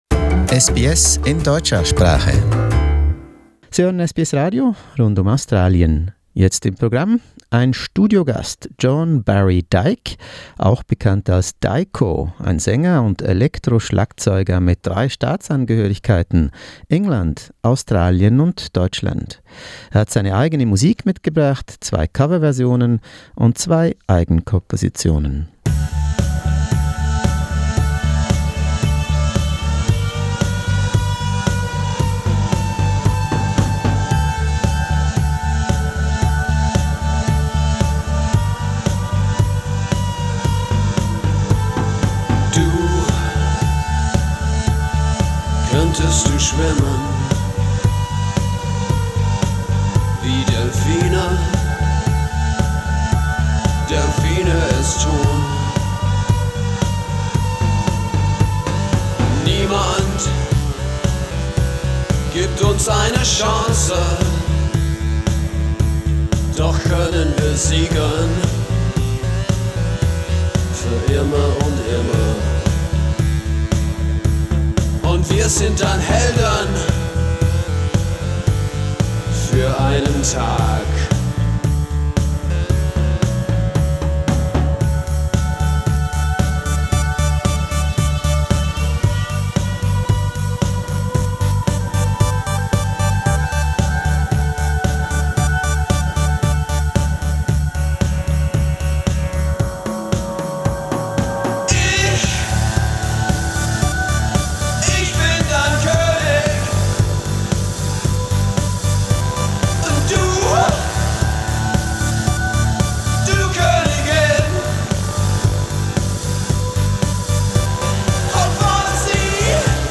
More about the singer, musician and song composer in a SBS studio talk.